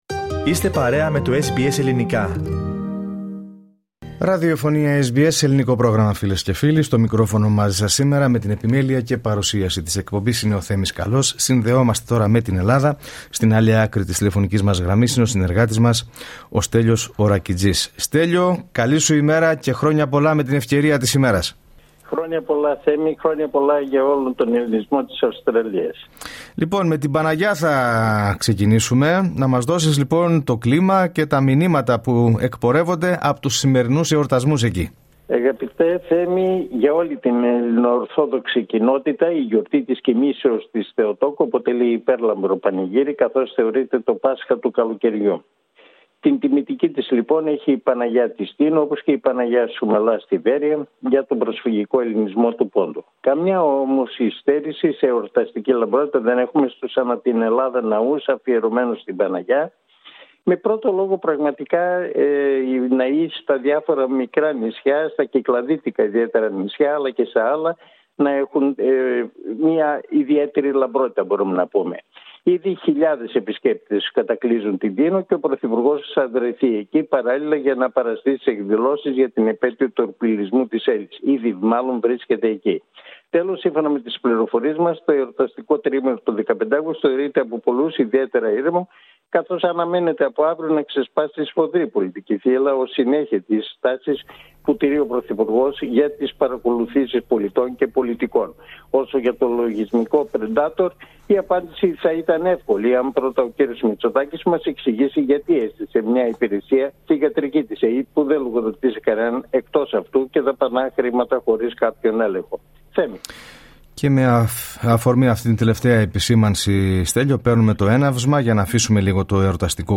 Η εβδομαδιαία ανταπόκριση από την Ελλάδα.